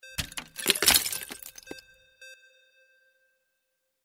Ice Break 05
Stereo sound effect - Wav.16 bit/44.1 KHz and Mp3 128 Kbps
Tags: ice
previewIMP_ICE_BREAK_WBHD05.mp3